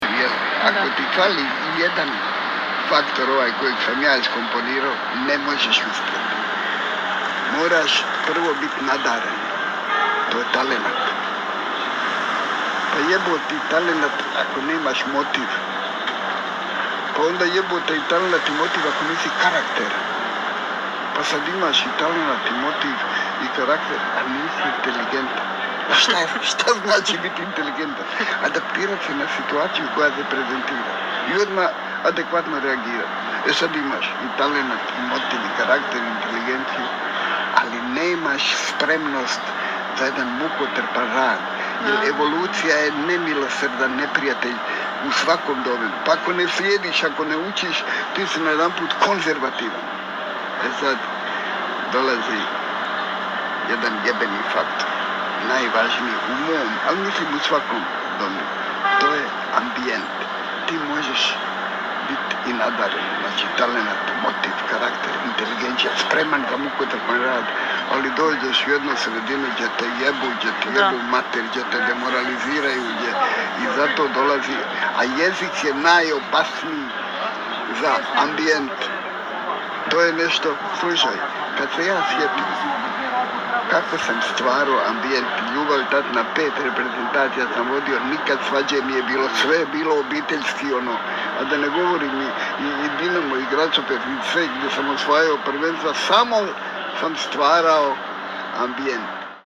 U intervjuu na terasi njegovog omiljenog kafića u Kraljevici, Ćiro je govorio, između ostalog, i o sedam važnih faktora.